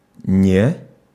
Ääntäminen
IPA : /nɒt/ IPA : /nɑt/